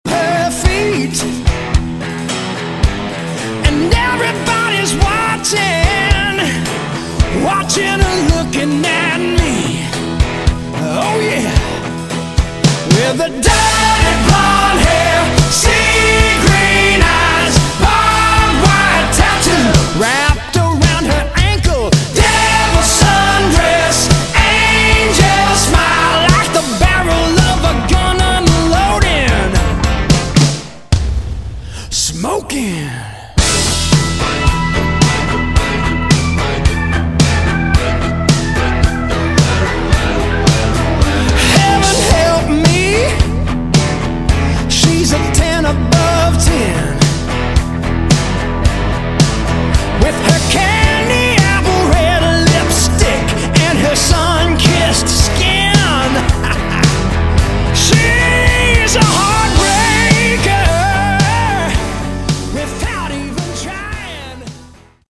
Category: AOR / Melodic Rock
vocals, keyboards, guitars